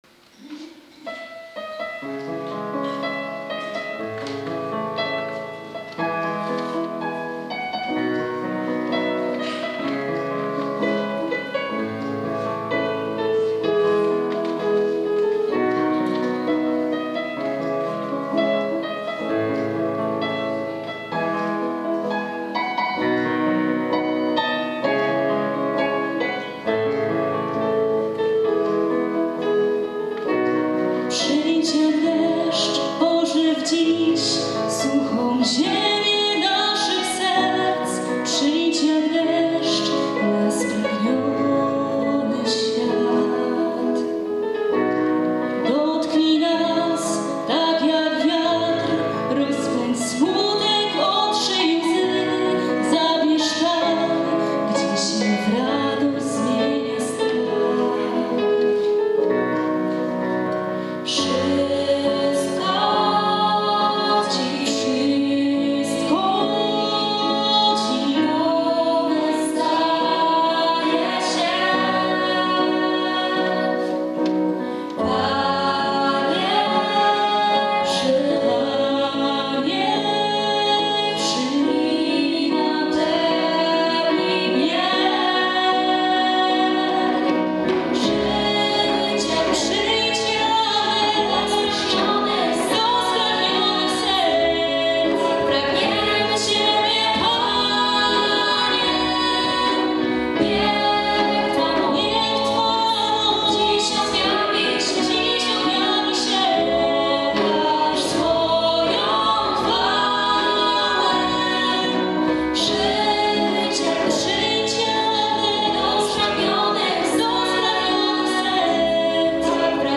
Spotkanie wielkanocne Powiatu Szydłowieckiego
W odświętny nastrój zebranych wprowadził zespół Vivo z gminy Mirów. Ta siedmioosobowa grupa studentów i maturzystów znakomicie dobranym repertuarem przyczyniła się do podkreślenia wielkanocnego charakteru spotkania.